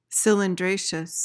PRONUNCIATION:
(sil-in-DRAY-shuhs)